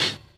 Closed Hats
Medicated Hat 1.wav